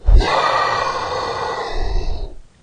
龙的声音 " 龙的咆哮 轻度6
描述：为制作史瑞克而制作的龙声。使用Audacity录制并扭曲了扮演龙的女演员的声音。
Tag: 生物 发声 怪物